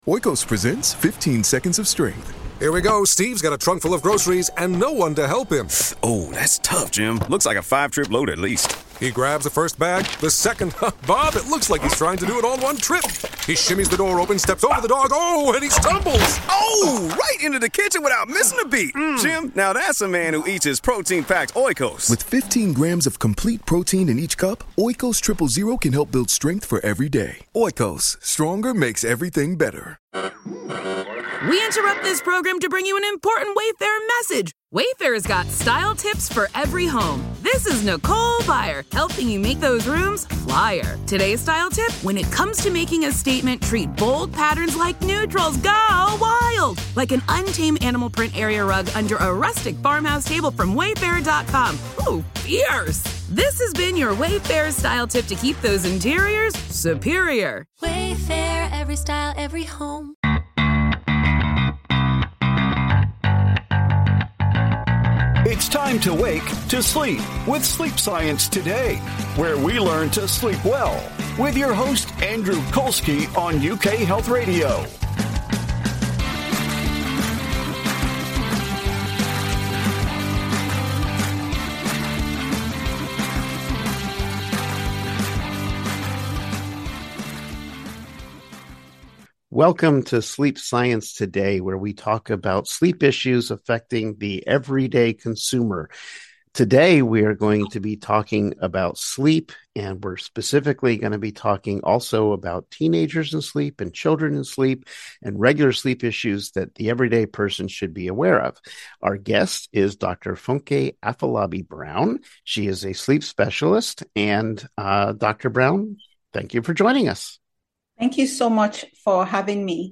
a show containing intriguing discussions about using sleep science to achieve better sleep.
You will hear from renowned sleep experts as they share the latest information about how to sleep better with science.